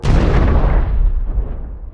bomb1.ogg